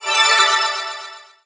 get_pickup_05.wav